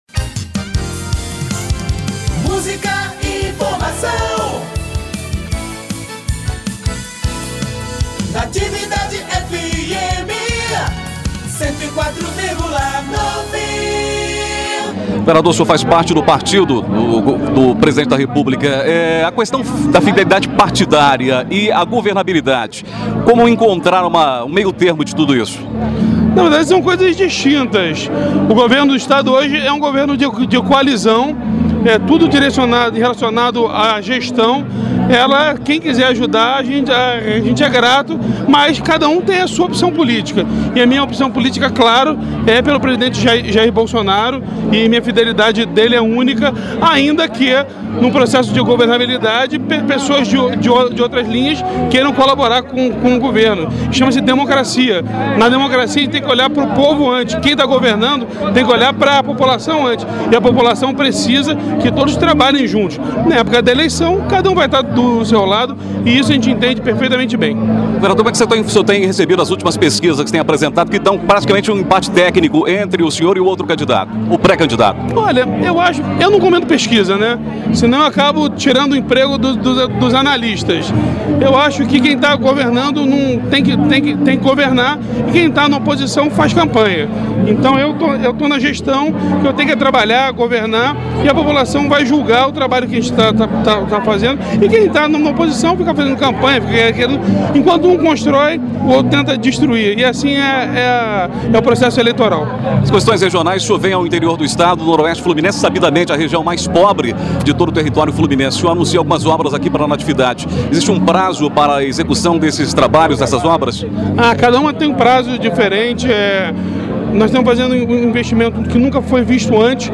Em entrevista à Rádio Natividade, governador fala sobre apoio ao presidente, pesquisas e investimentos no interior – OUÇA
Entrevista concedida à Rádio Natividade durante visita a cidade no final da tarde desta terça-feira (10), o governador Cláudio Castro (PL), falou sobre seu apoio ao presidente Jair Bolsonaro, mas declarou que o governo está aberto a quem quiser ajudar.